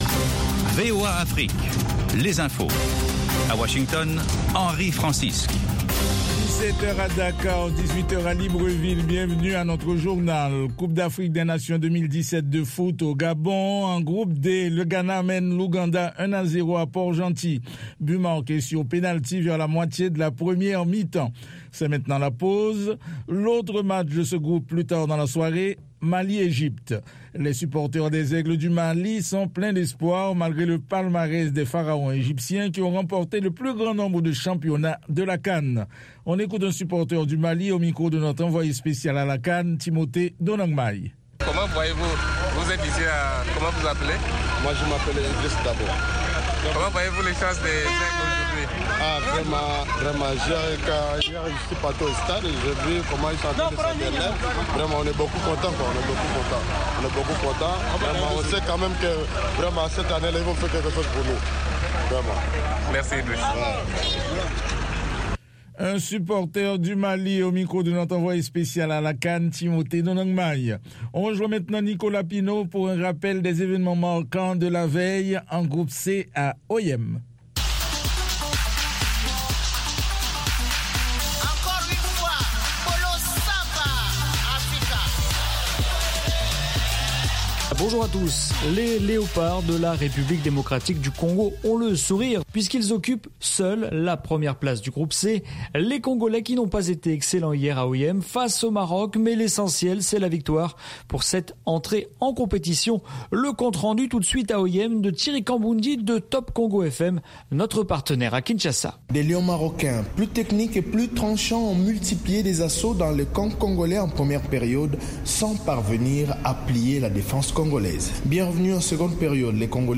10 min News French